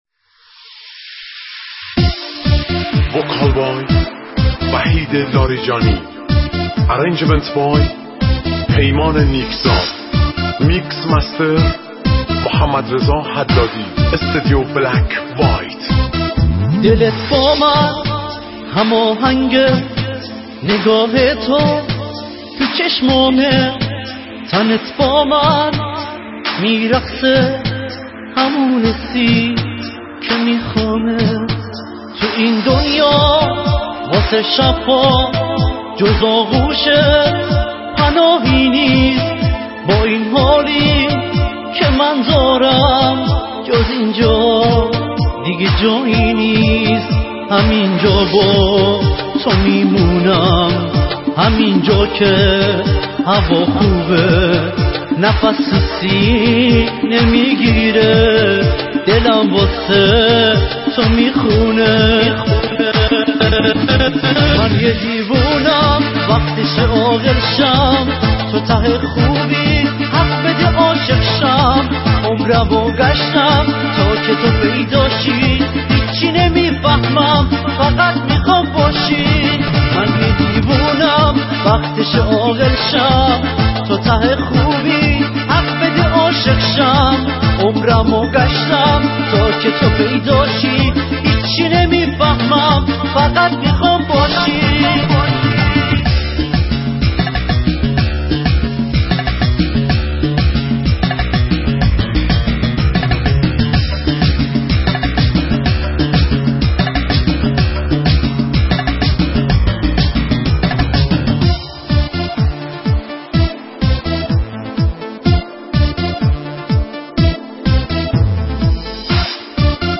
آهنگ شاد